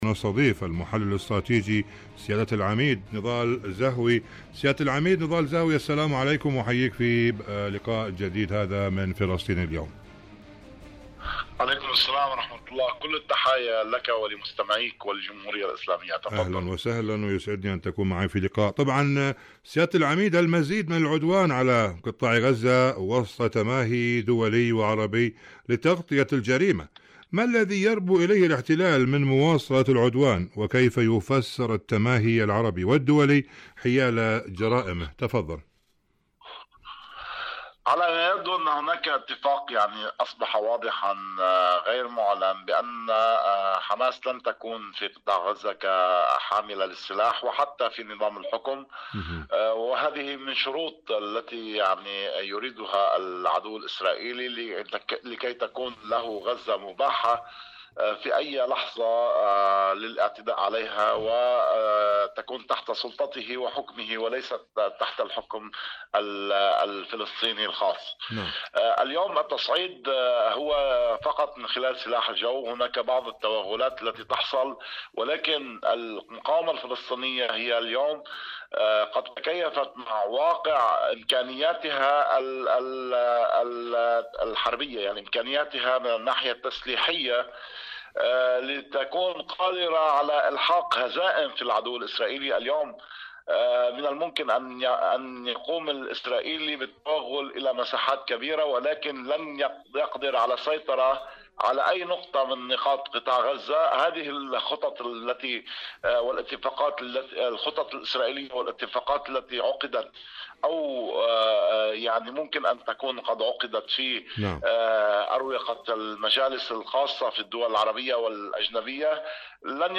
غزة بين تطبيع الجريمة والإصرار على المواجهة.. مقابلة